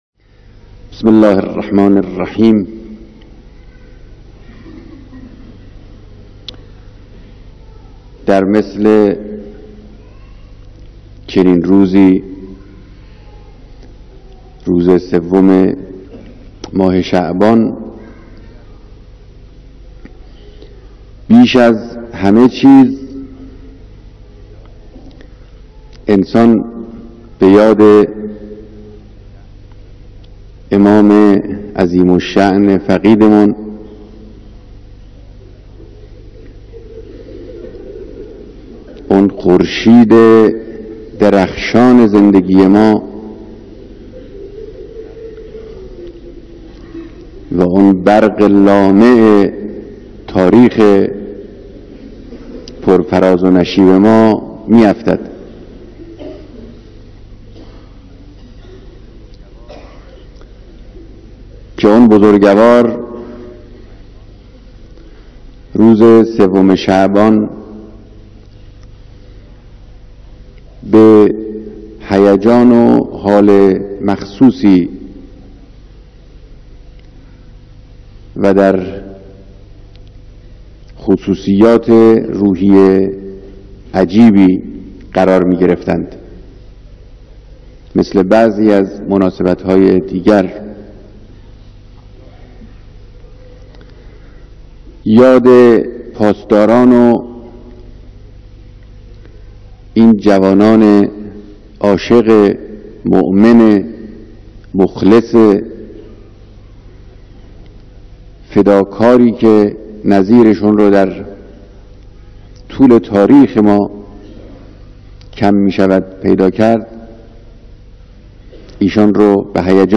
ديدار فرماندهان، مسؤولين و پرسنل نيرو هاي نظامي و انتظامي به مناسبت روز پاسدار
بیانات رهبر انقلاب در دیدار پاسداران